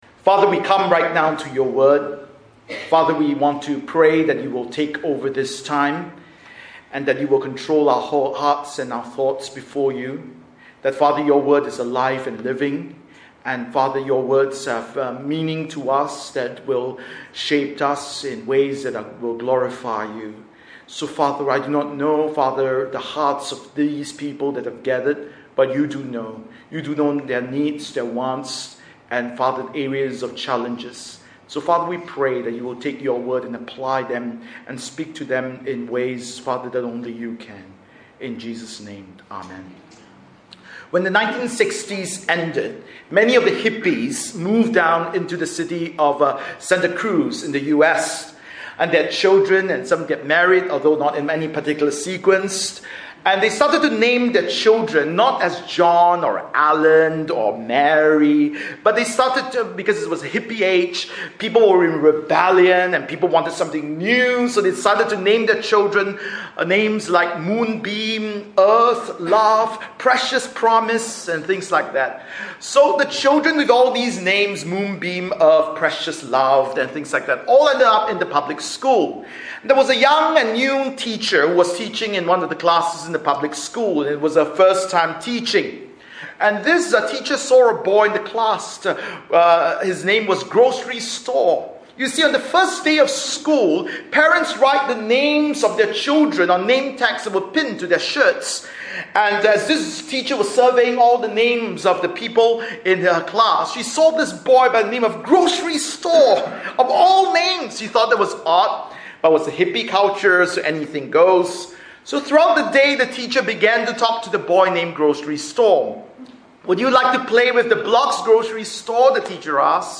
Bible Text: 1 Samuel 4:1-11 | Preacher